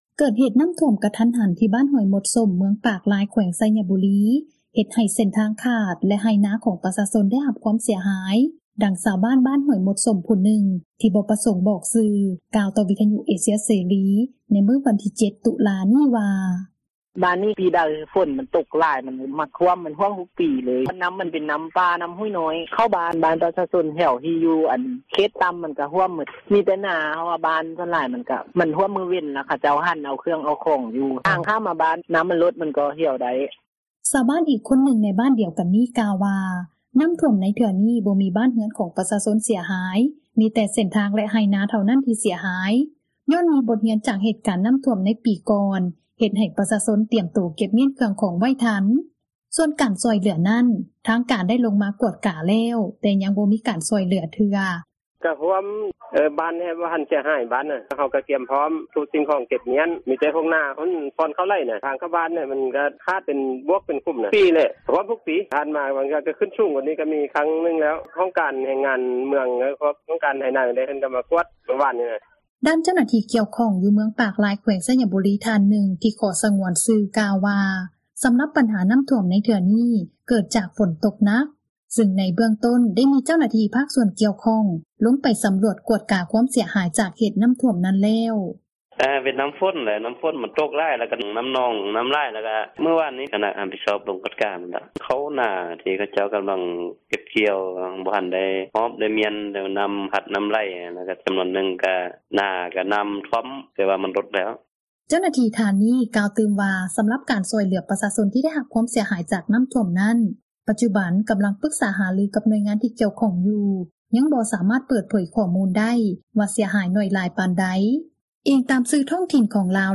ເກີດເຫດນໍ້າຖ້ວມ ກະທັນຫັນ ທີ່ ບ້ານຫ້ວຍມົດສົ້ມ ເມືອງປາກລາຍ ແຂວງໄຊຍະບູຣີ ເຮັດໃຫ້ເສັ້ນທາງຂາດ ແລະ ໄຮ່ນາຂອງປະຊາຊົນ ເສັຍຫາຍ, ດັ່ງຊາວບ້ານ ບ້ານຫ້ວຍມົດສົ້ມ ຜູ້ນຶ່ງ ທີ່ບໍ່ປະສົງບອກຊື່ ກ່າວຕໍ່ວິທຍຸເອເຊັຽເສຣີ ໃນວັນທີ 7 ຕຸລາ ນີ້ວ່າ: